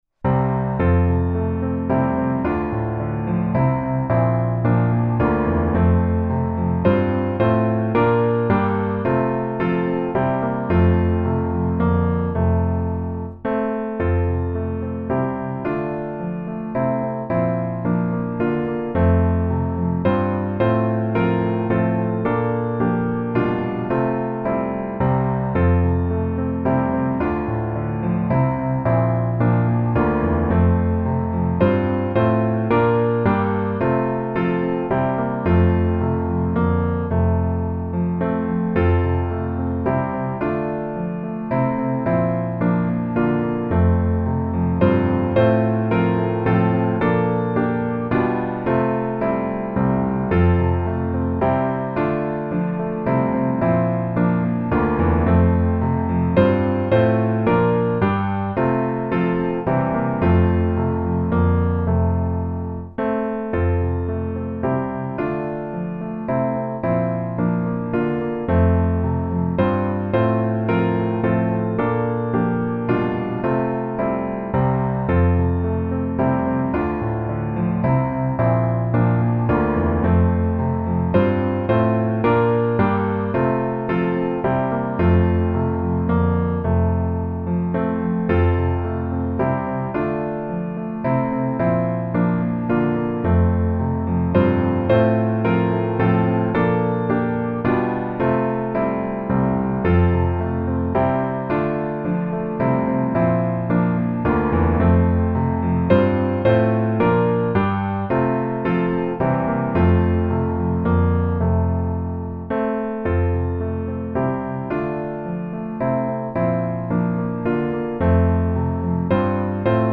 Piano Hymns
F Major